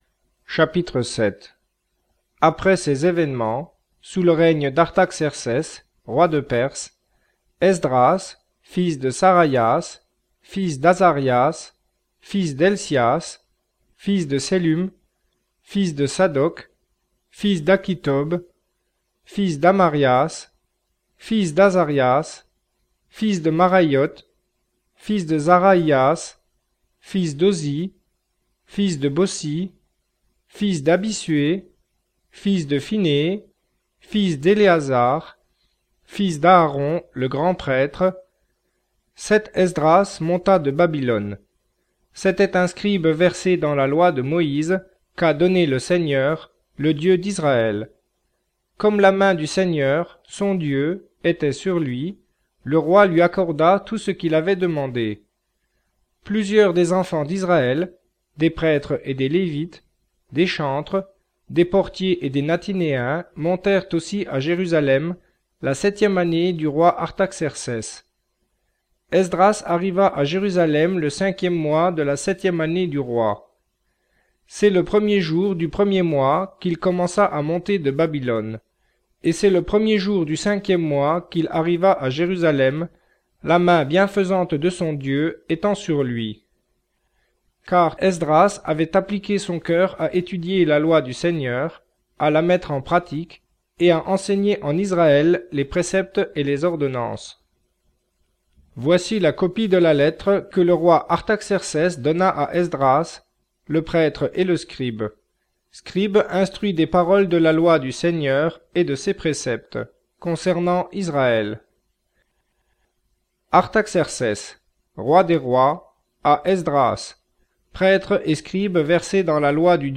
Il convient donc de les remplacer à la lecture par "le Seigneur" voir 'l'Éternel".
Cette précision apportée, le texte lu est aussi fidèle que possible à l'écrit.